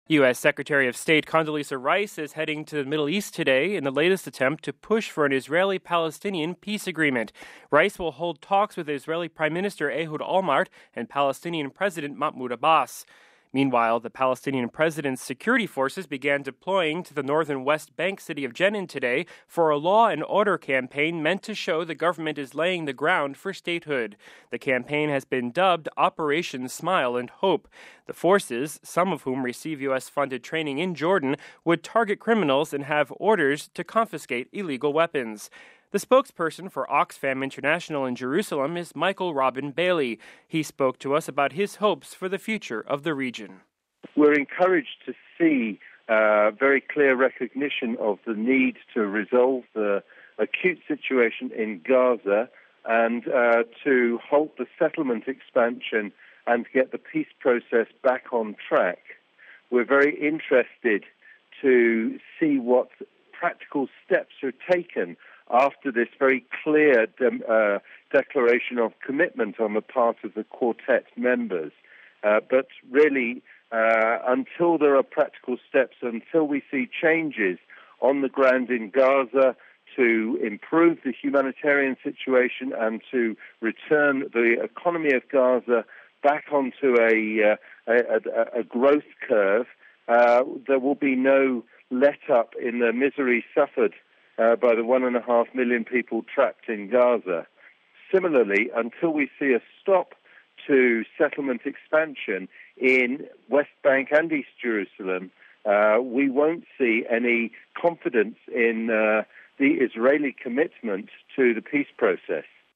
He spoke to us about his hopes for the future of the region…